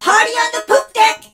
darryl_lead_vo_01.ogg